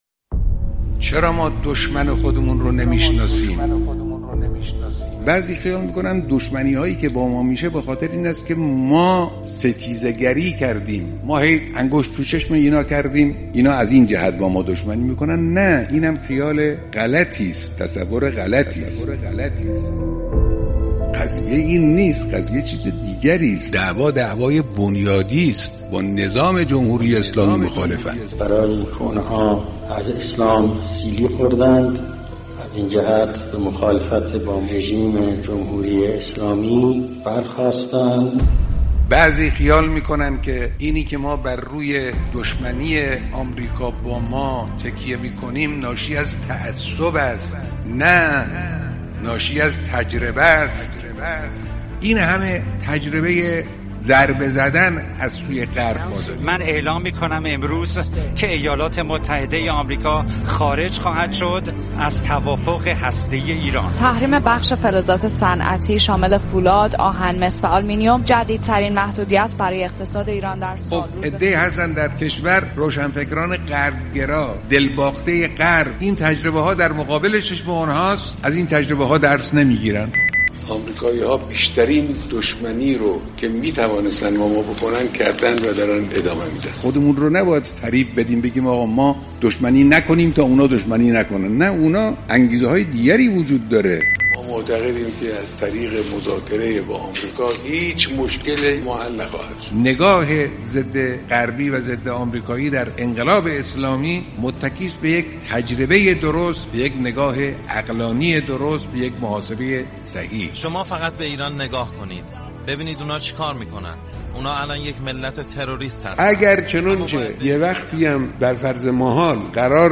کلیپ صوتی"تجربه عقلانی" که برشی از بیانات رهبر انقلاب درباره علل دشمنی با نظام اسلامی و دلیل عدم مذاکره با آمریکا است.